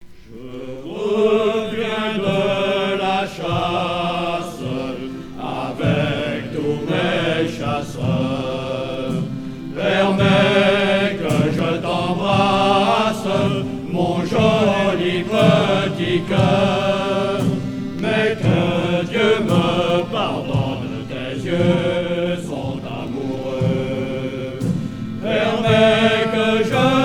Genre strophique
En direct du prieuré Saint-Nicolas
Pièce musicale éditée